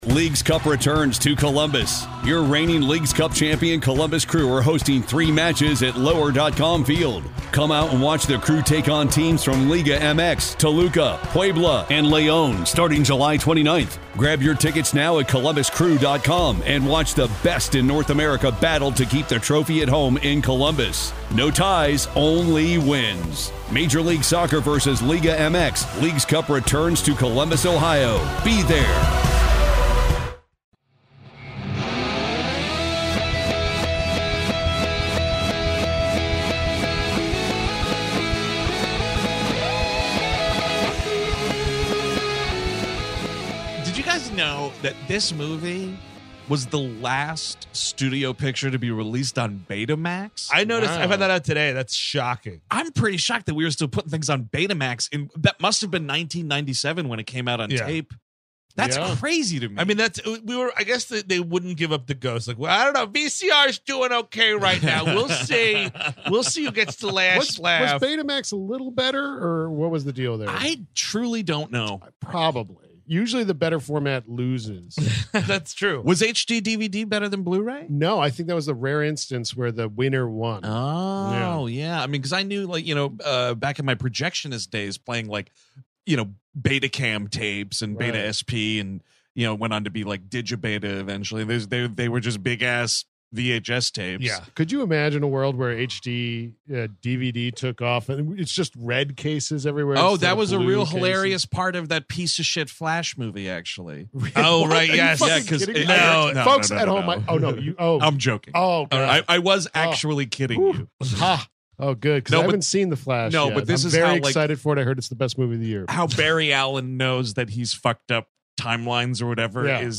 Tv & Film, Comedy
On this month’s W❤M episode, the gang chats about the incredible espionage action flick that started it all, Brian De Palma’s Mission: Impossible!